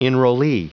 Prononciation du mot enrollee en anglais (fichier audio)
Prononciation du mot : enrollee